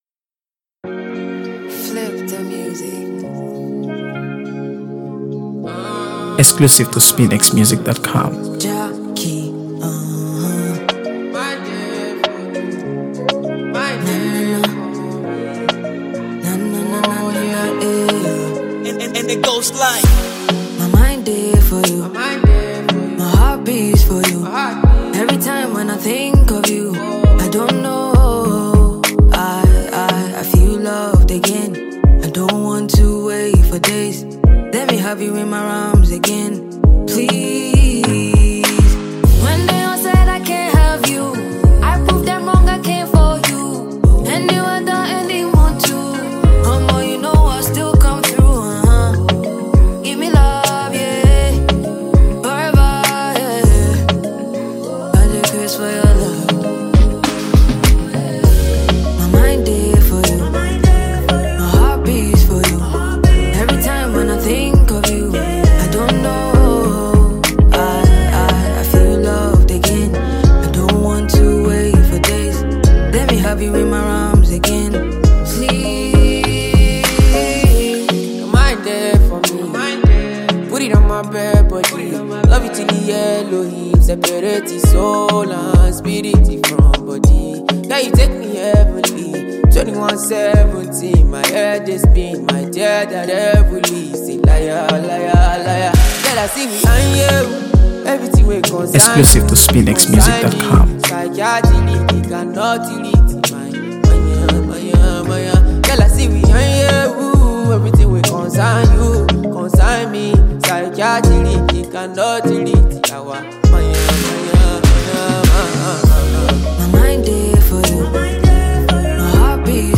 Afro-Fusion/Afrobeats